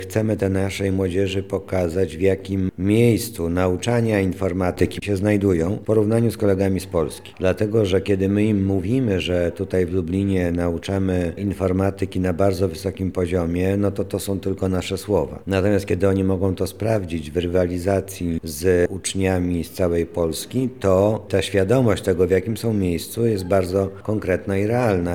Dzisiaj (17 marca) w Lublinie odbyła się inauguracja IT Fitness Test 2026.
O tym, jakie korzyści wynikają z testu, mówi Zastępca Prezydenta ds. Oświaty i Wychowania Mariusz Banach: